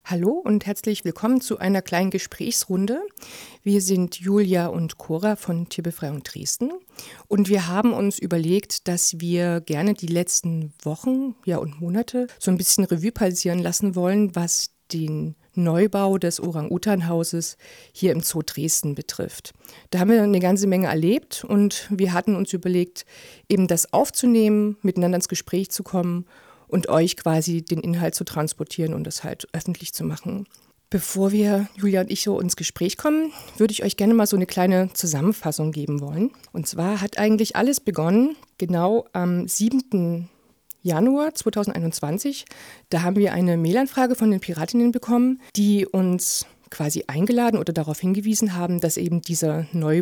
orangutan.mp3